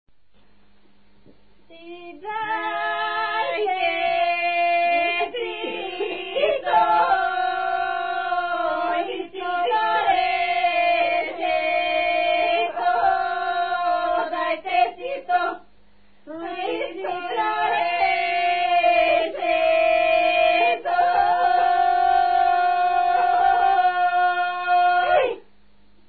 музикална класификация Песен
форма Двуредична /от 1-ред. с повт./
размер Безмензурна
фактура Двугласна
начин на изпълнение Група (на отпяване)
битова функция На сватба
фолклорна област Средна Западна България
място на записа Смочево
начин на записване Магнетофонна лента